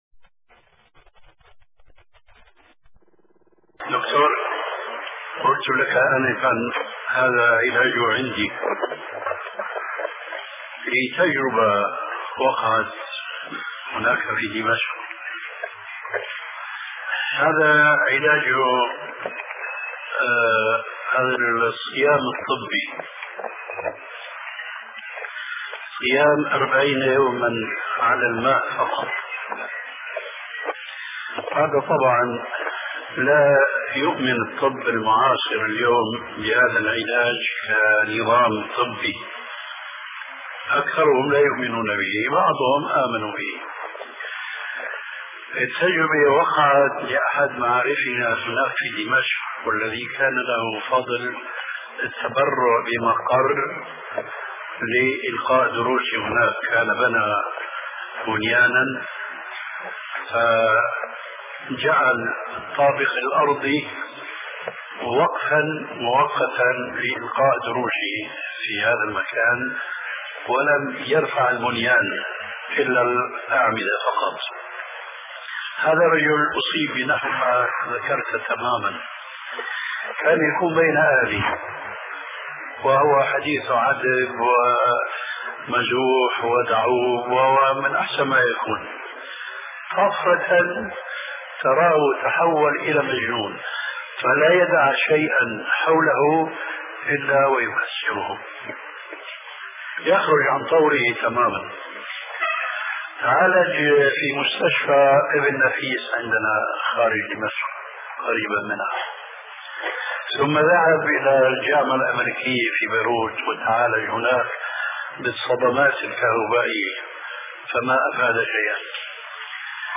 شبكة المعرفة الإسلامية | الدروس | التطبب بالصيام |محمد ناصر الدين الالباني